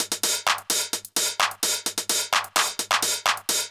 CLF Beat - Mix 17.wav